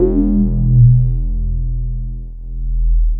JUP 8 E2 6.wav